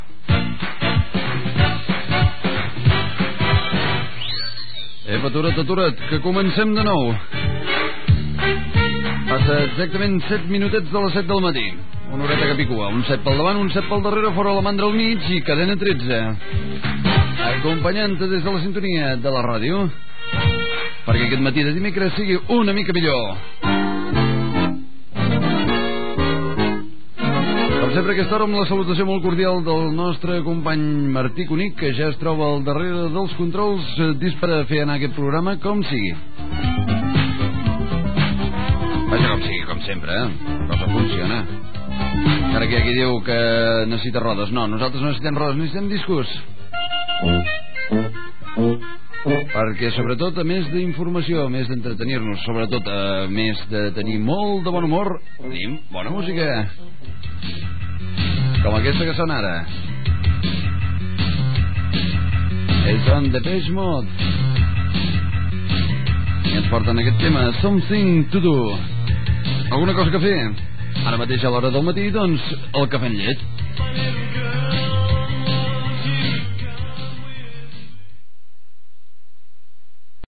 Hora, presentació i tema musical
Entreteniment